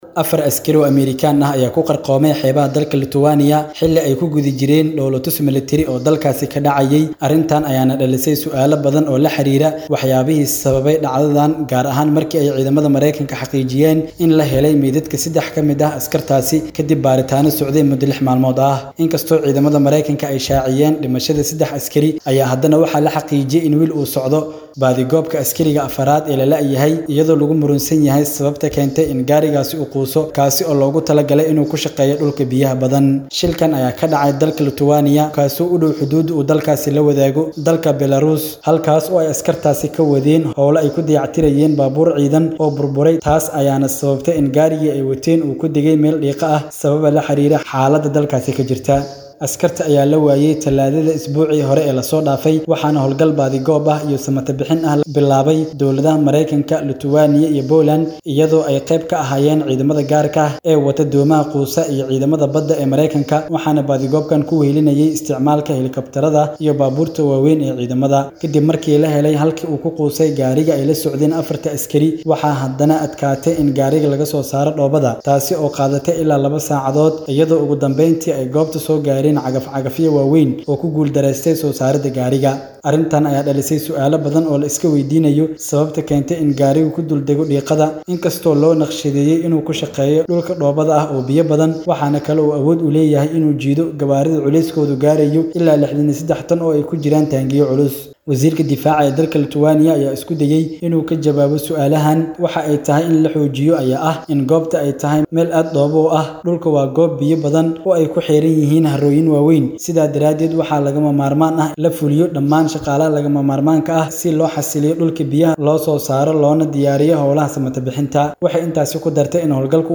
Warbixin: Ciidamo Ameerikaan ah oo ku Dhintay Dalka Lutuwaaniya. [Dhagayso].
Warbixin-Ciidamo-Ameerikaan-ah-oo-ku-dhintay-Lutuwaaniya.mp3